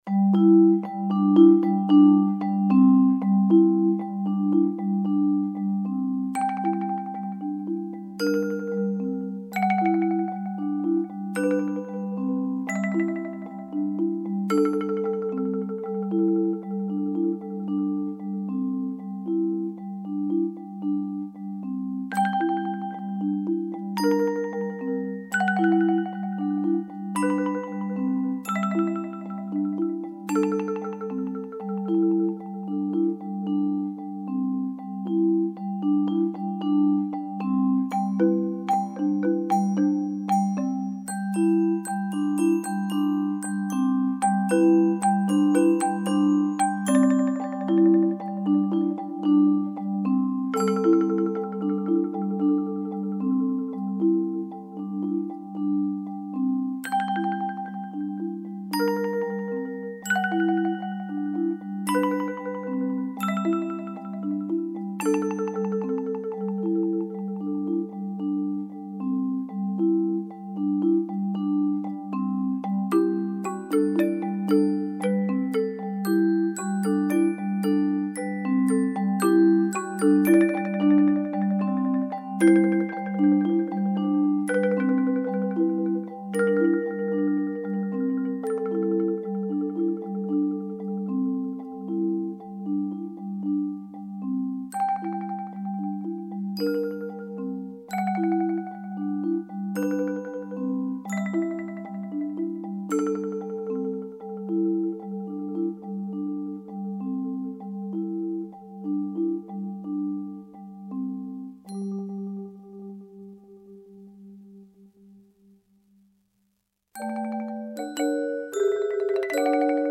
Voicing: Mallet Quartet